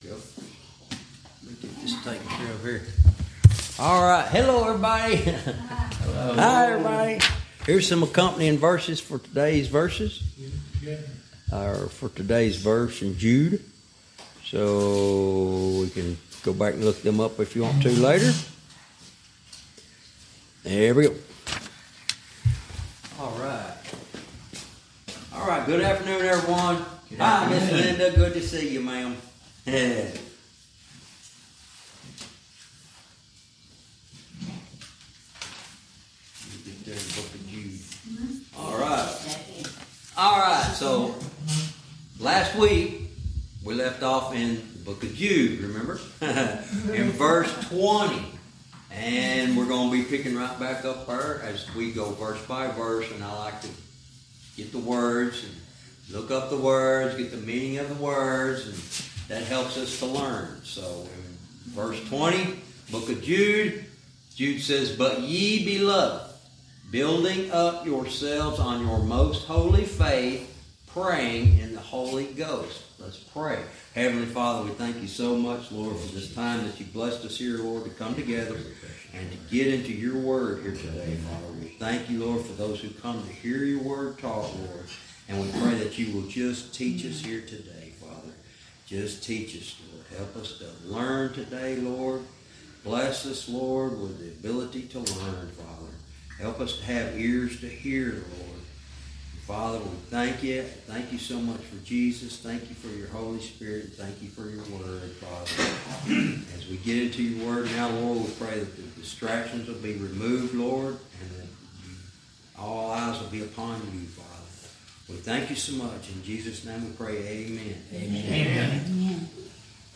Verse by verse teaching - Jude lesson 90 verse 20